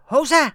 traf_oops1.wav